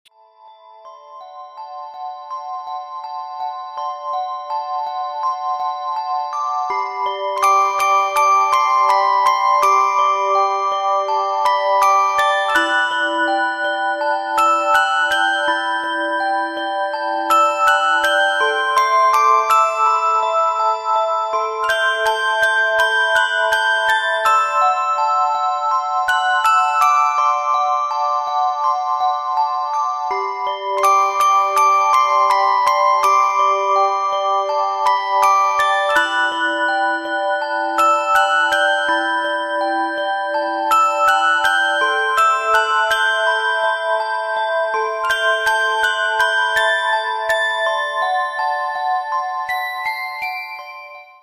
• Качество: 192, Stereo
спокойные
без слов
красивая мелодия
инструментальные
колокольчики
Приятная мелодия